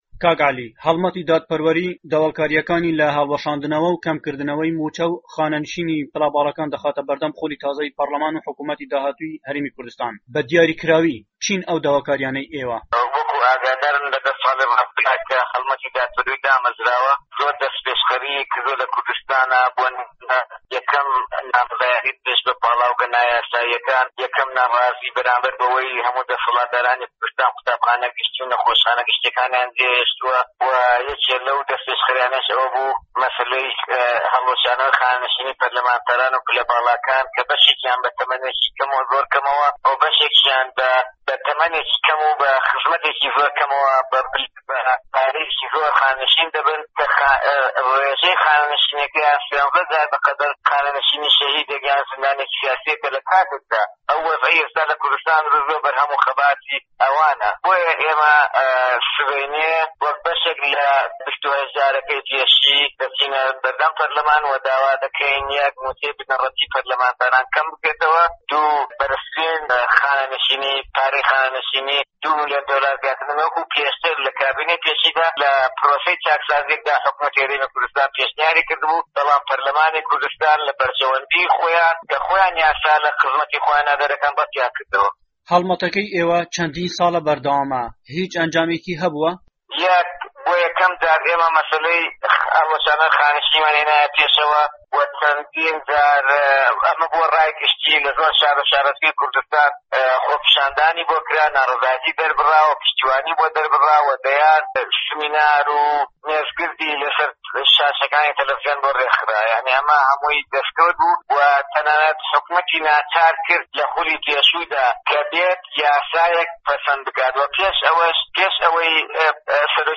وتووێژێکدا